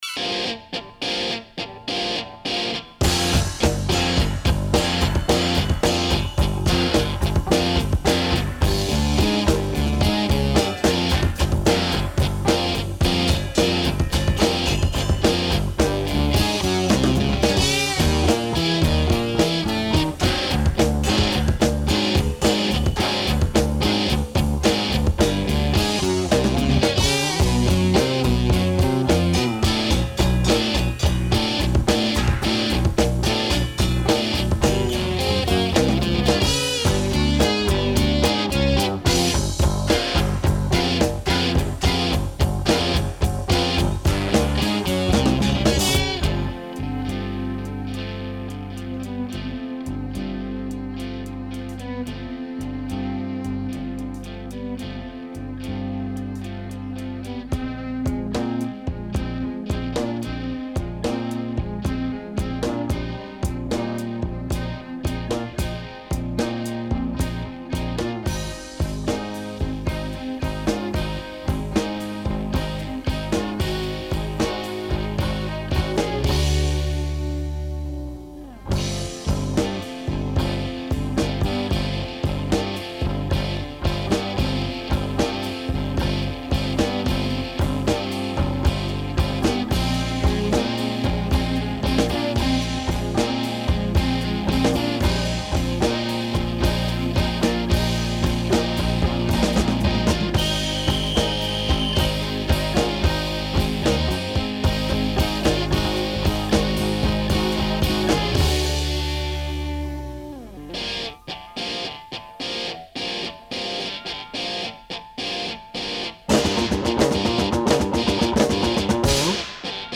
Жанр: Art Rock, Progressive Rock, Experimental Rock